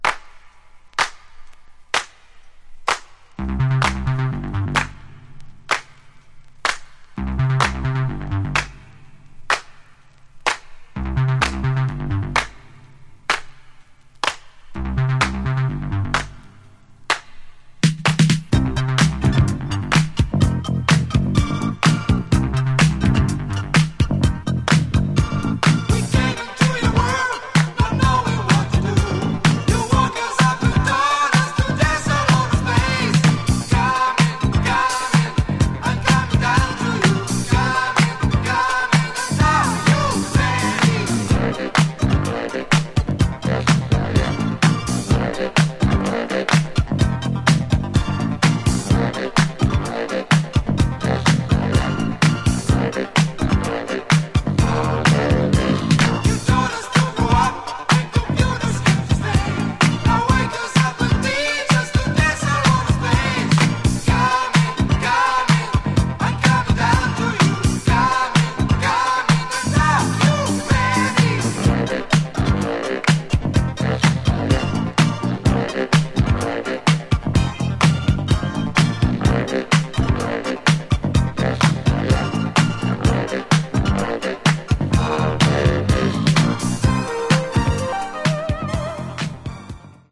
Genre: Techno/Synth Pop